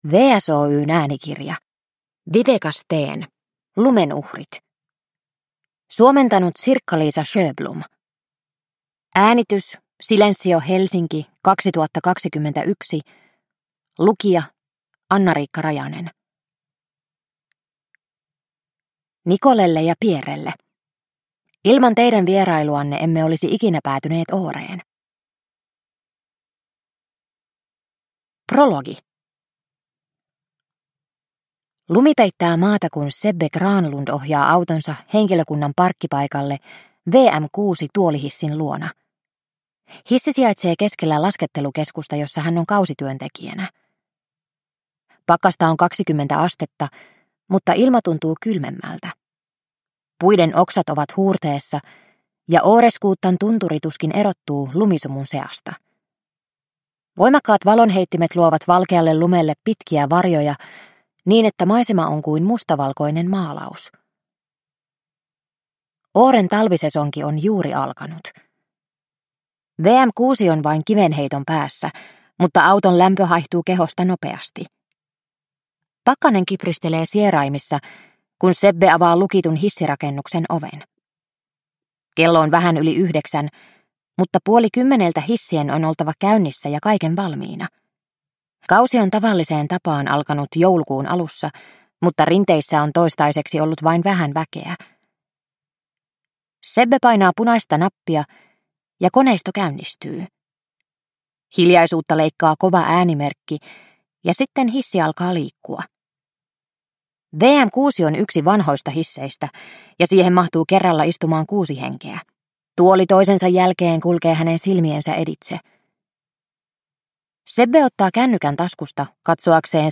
Lumen uhrit – Ljudbok – Laddas ner